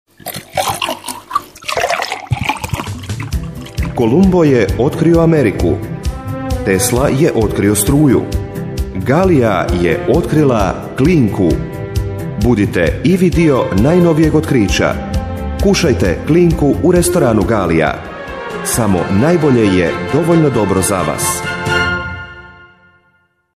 Experienced voice-over talent for Croatian, Bosnian and Serbian market.
Sprechprobe: Industrie (Muttersprache):